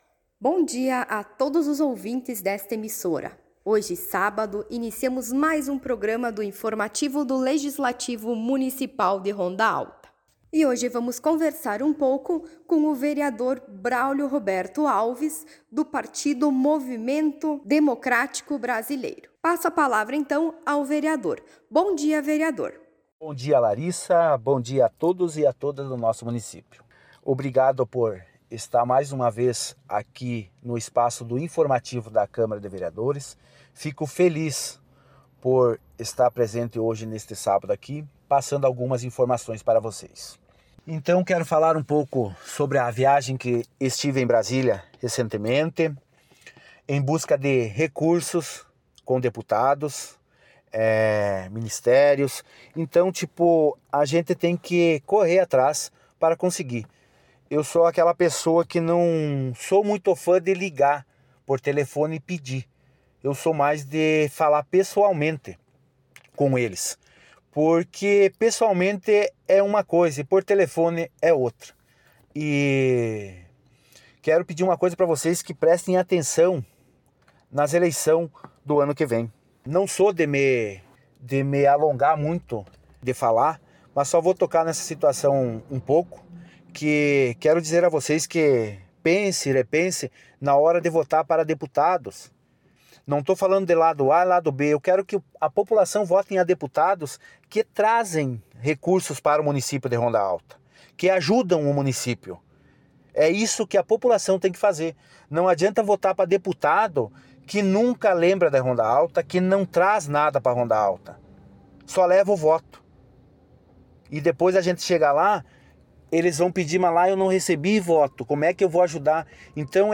Informativos radiofônicos